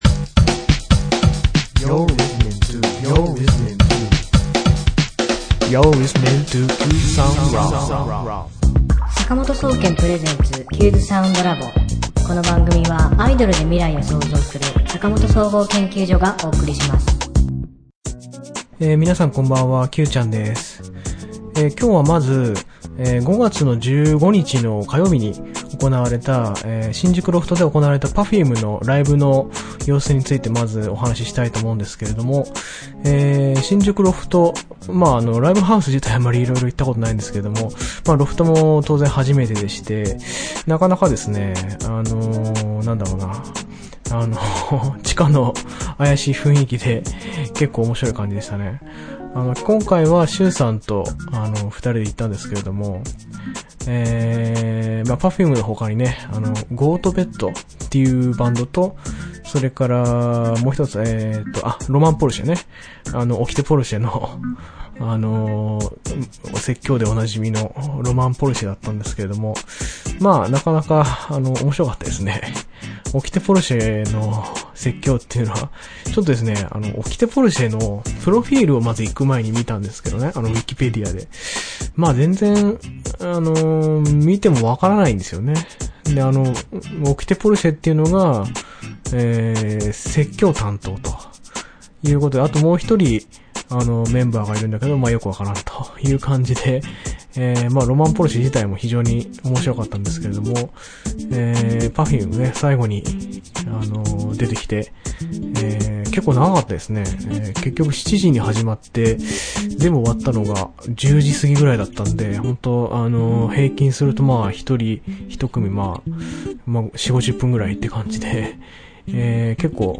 ねえねえ、それよりもまたまたＱの新曲（サンバ風）のイメージをジングルにまとめましたので、ぜひ聴いてください！。
作詞・作曲・編曲・コーラス・歌：坂本総合研究所